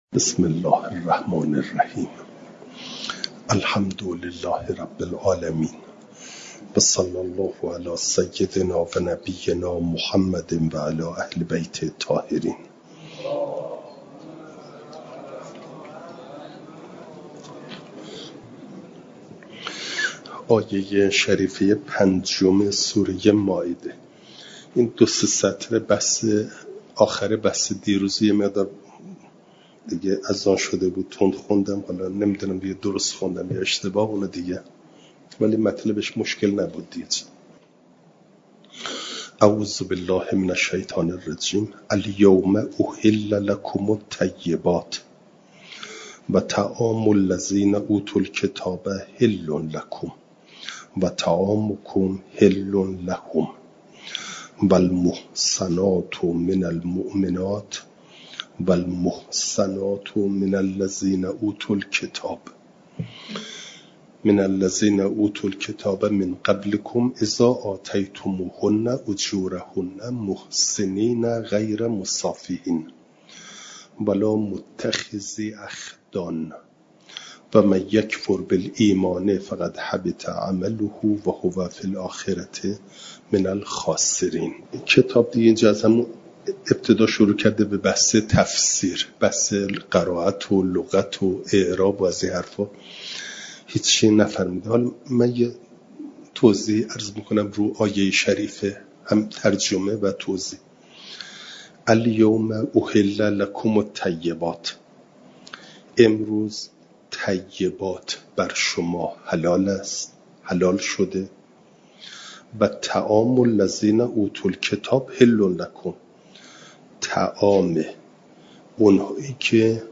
جلسه چهارصد و هفده درس تفسیر مجمع البیان